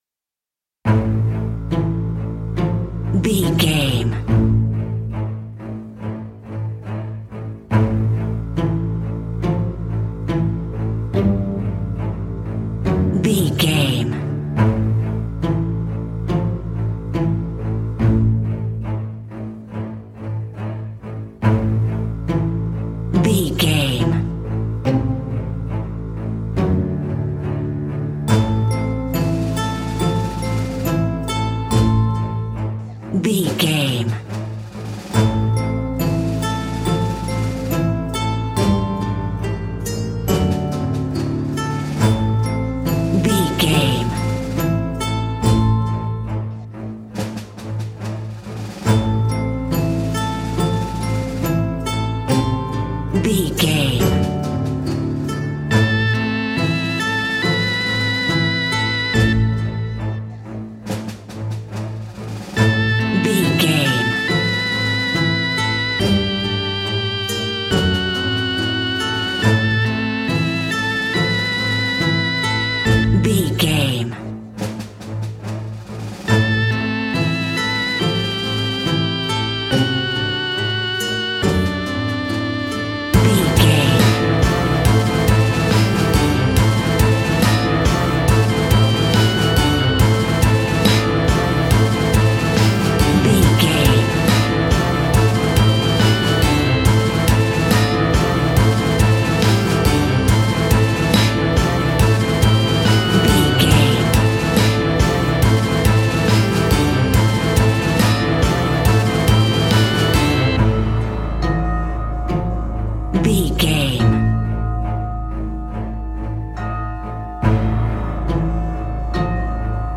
Aeolian/Minor
ominous
eerie
electric organ
strings
acoustic guitar
harp
synthesiser
drums
percussion
creepy
spooky
horror music